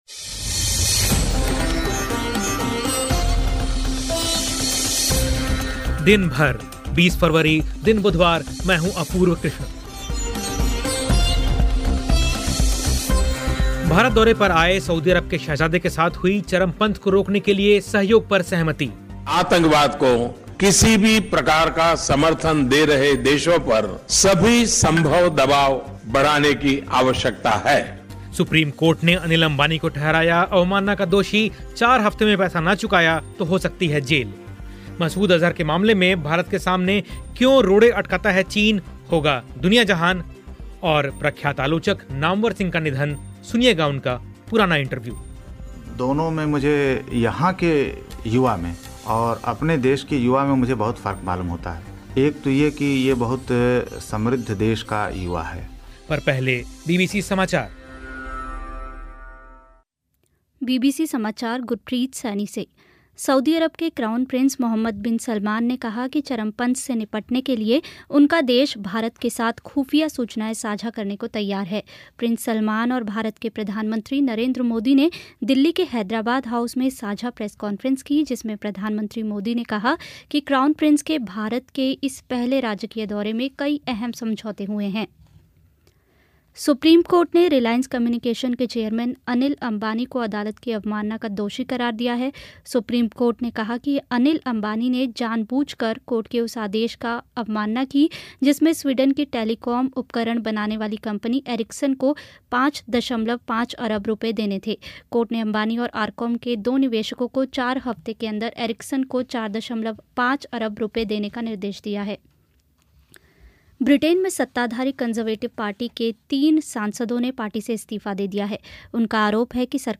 प्रख्यात आलोचक नामवर सिंह का निधन, सुनिए उनका एक पुराना इंटरव्यू